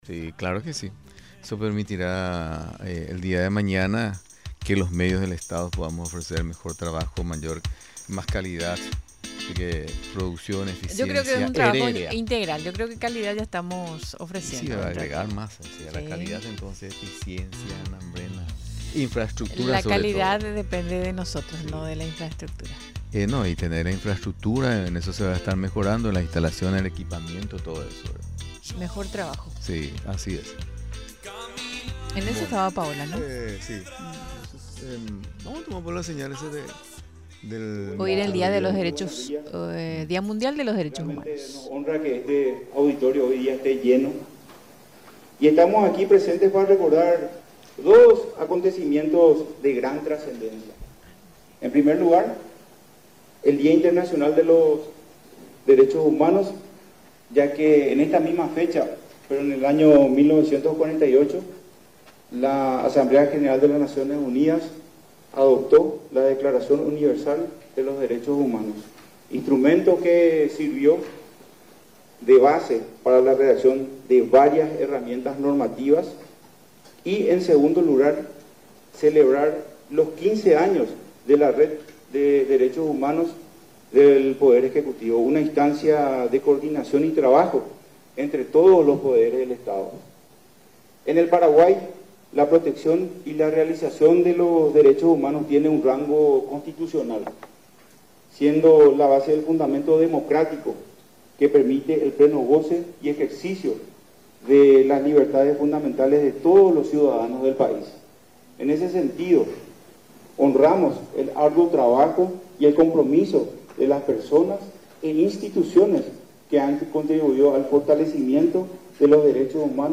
La ceremonia se desarrolló en el salón auditorio del Instituto del Banco Central del Paraguay (IBCP).